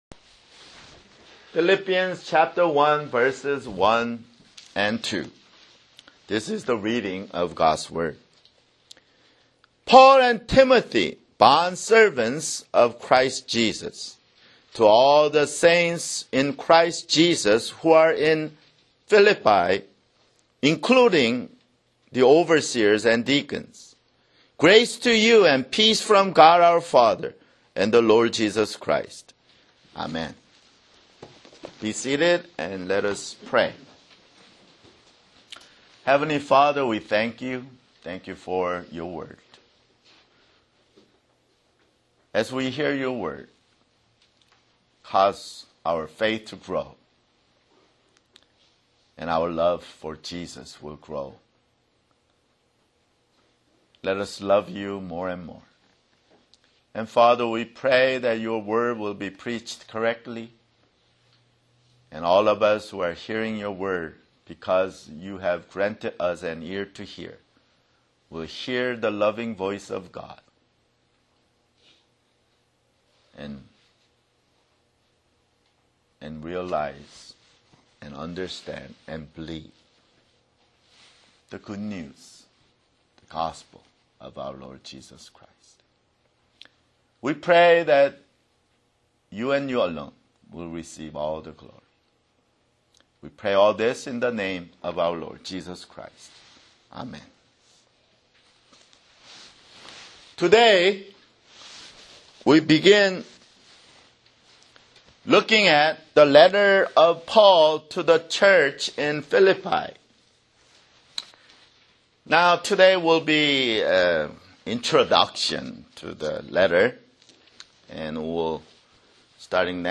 [Sermon] Philippians (1)